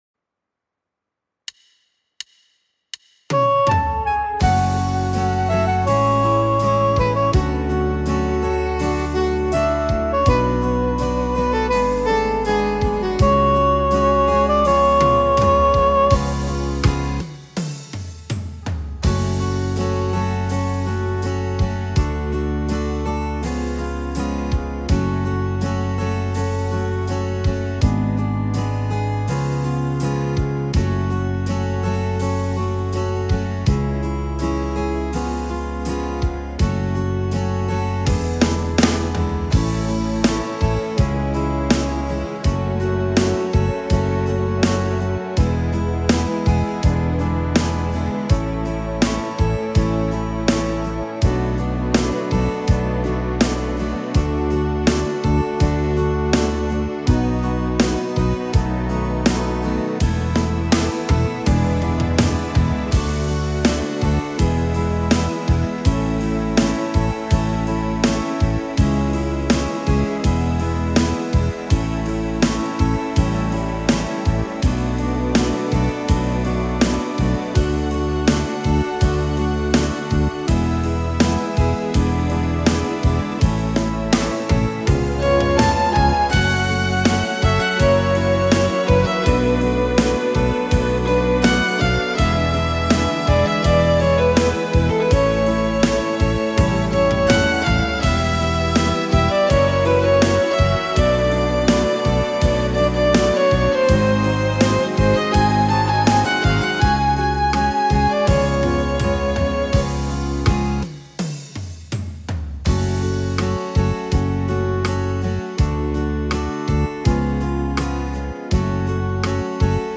Tone Nữ (A)
•   Beat  01.